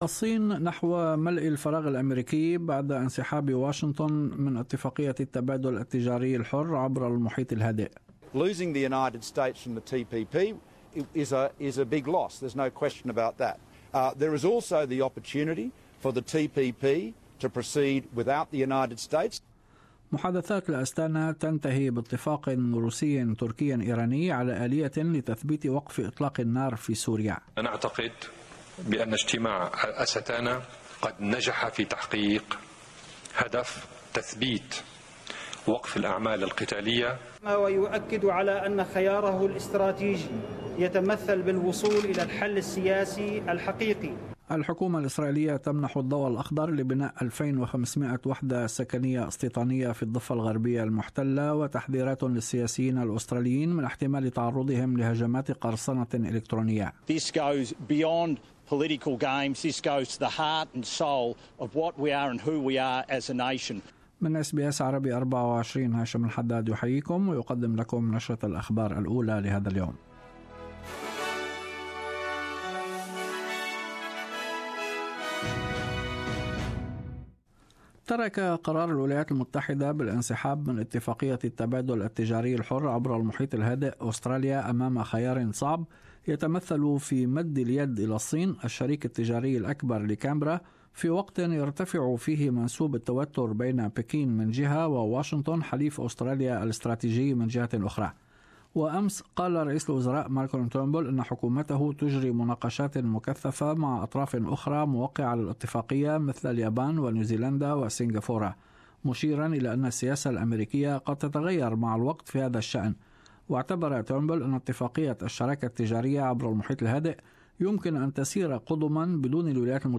The Morning News Bulletin